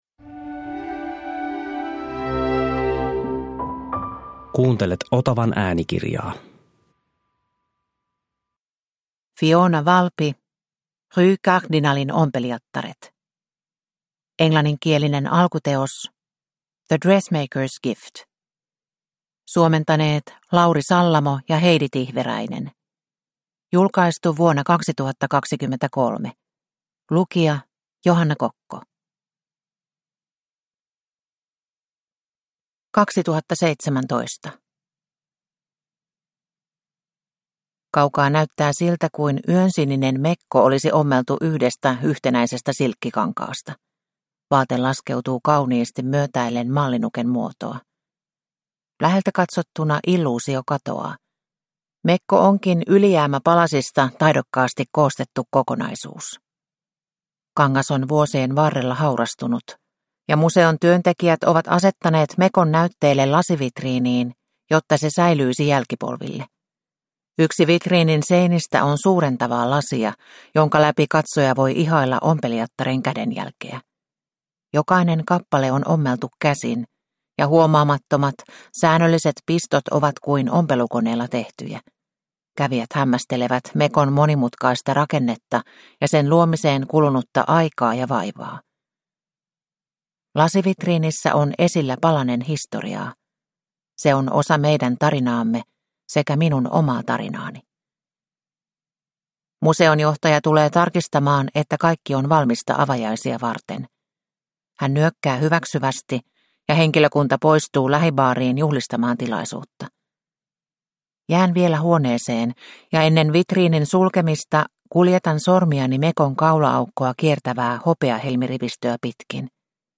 Rue Cardinalen ompelijattaret – Ljudbok – Laddas ner